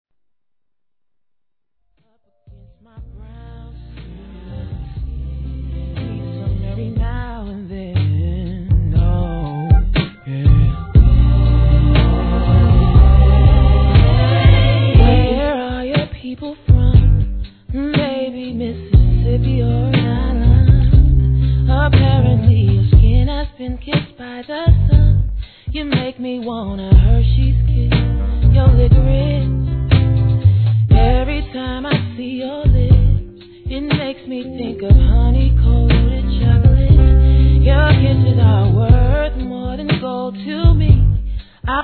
HIP HOP/R&B
スロウテンポでがっちり歌声を聴かせてくれます。